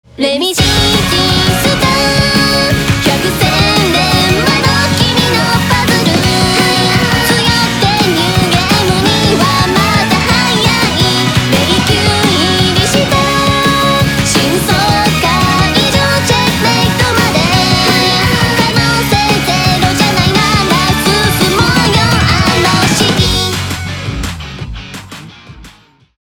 ドラマ - Drama Part -